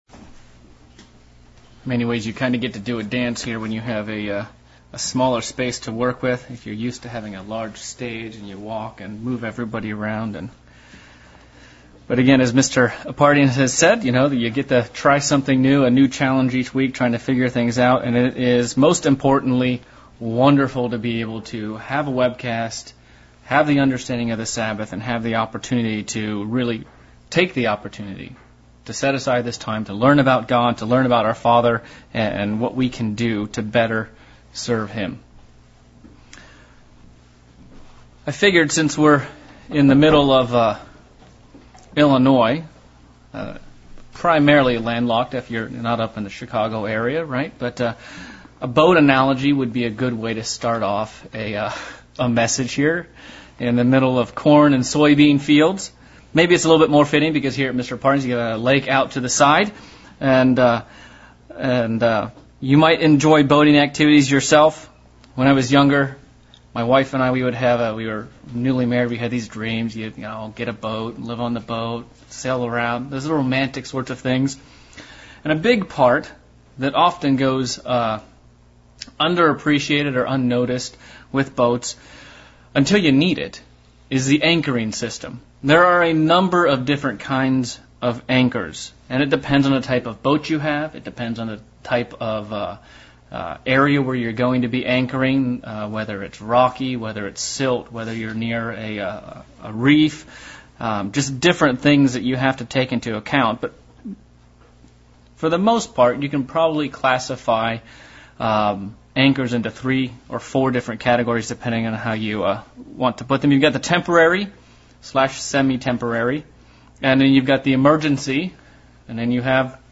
Sermon looking at different types of anchors and attachment ponts and compaing to our spiritual anchor and what it is anchored to.